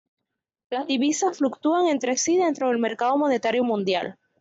Pronounced as (IPA) /ˈsi/